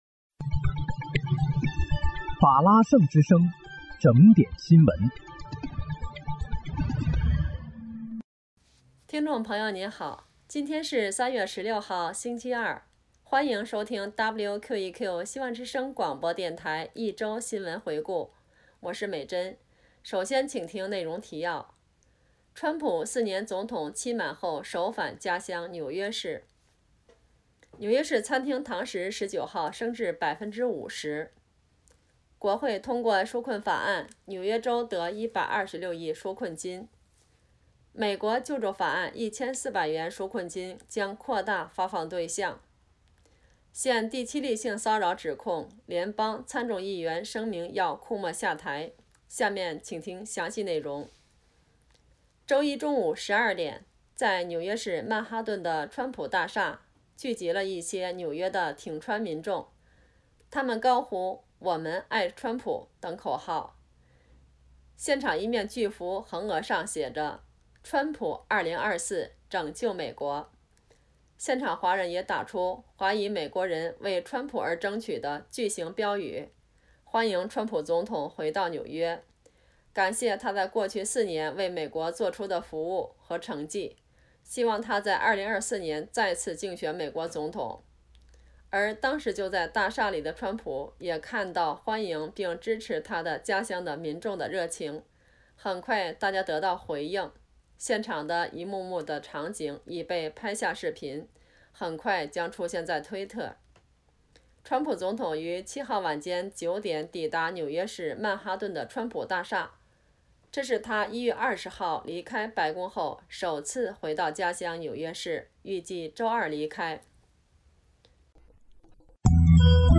3月14日（星期日）一周新闻回顾
听众朋友您好！今天是3月14号，星期日，欢迎收听WQEQFM105.5法拉盛之声广播电台一周新闻回顾。